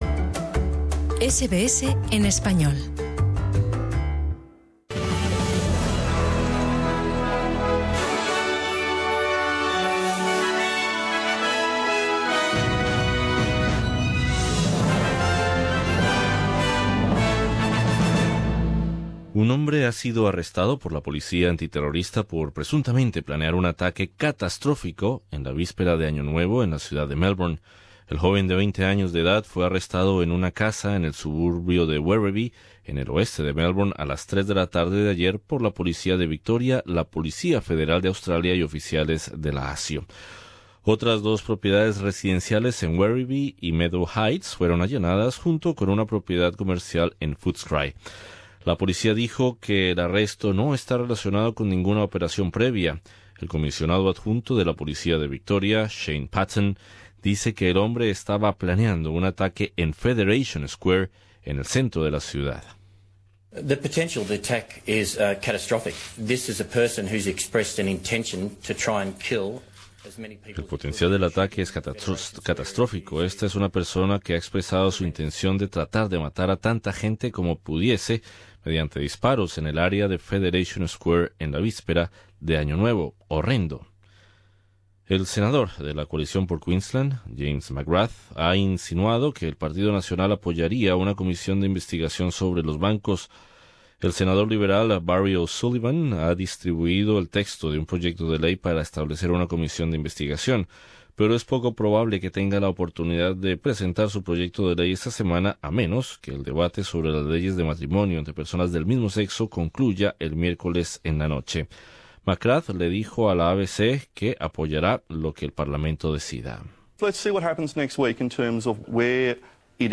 Escucha las noticias australianas del 28 de noviembre de 2017